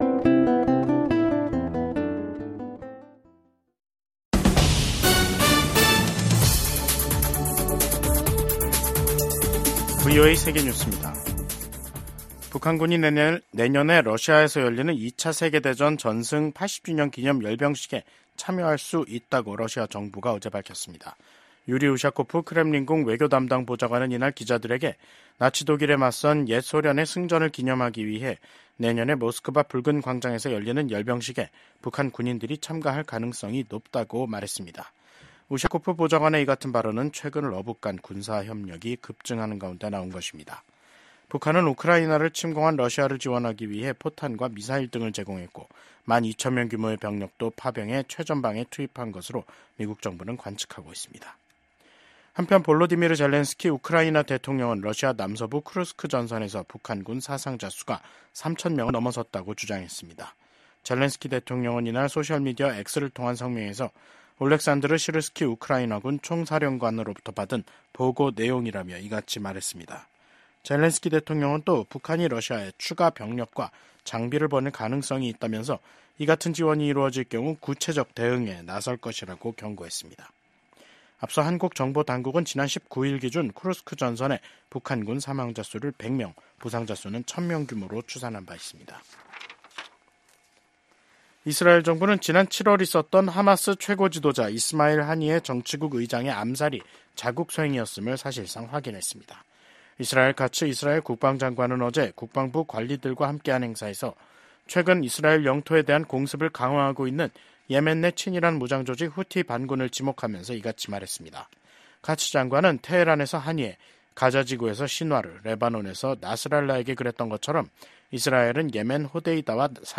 VOA 한국어 간판 뉴스 프로그램 '뉴스 투데이', 2024년 12월 24일 2부 방송입니다. 러시아 당국은 내년 5월 전승절 행사에 북한 군이 참여할 수 있다고 밝혔습니다. 미국과 한국 정부가 최근 한국의 ‘비상계엄’ 사태 등으로 연기됐던 주요 외교, 안보 일정을 재개하기로 합의했습니다. 미국의 한반도 전문가들은 한국에서 진보 정부가 출범하면 ‘진보적 대북 정책’을 실현하기 위해 동맹을 기꺼이 희생할 것이라고 전망했습니다.